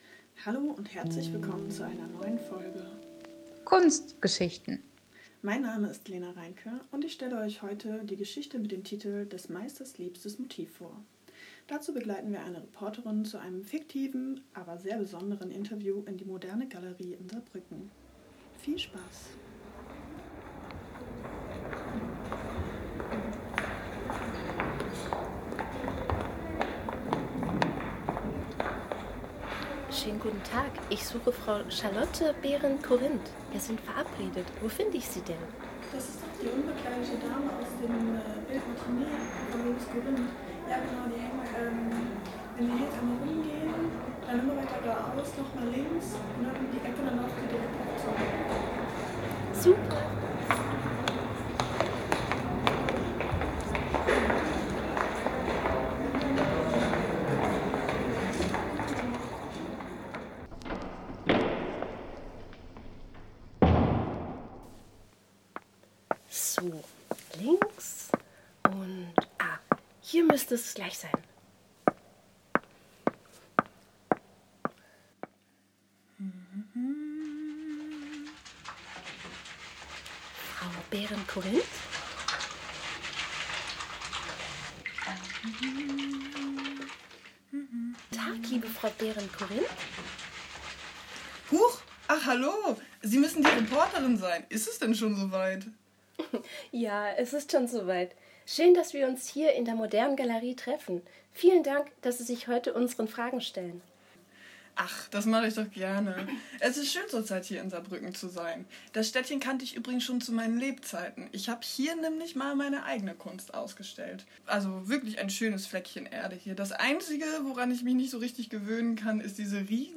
Lovis Corinth liebte seine Frau Charlotte und wählte sie gern als Motiv für seine ausdrucksstarken Werke aus. In einem fiktiven Interview mit der jungen Portraitierten finden wir mehr über ihre Gefühlswelt heraus.